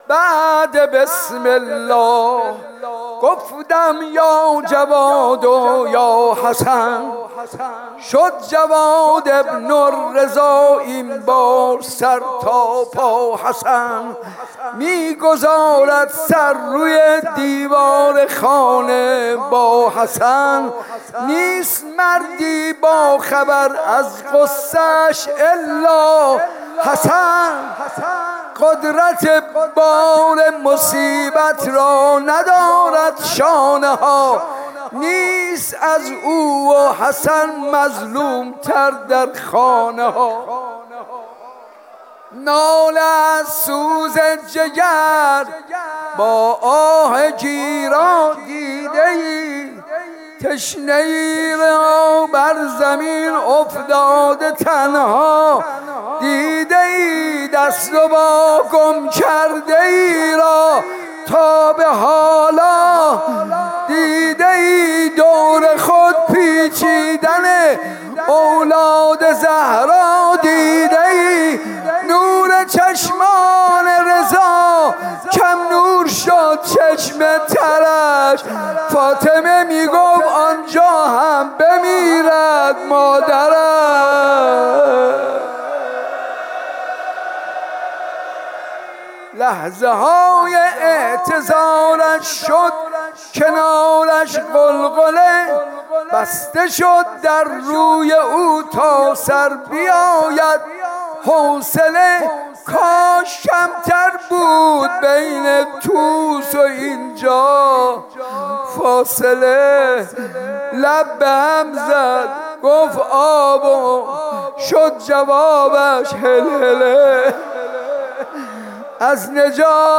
مداحی به سبک روضه اجرا شده است.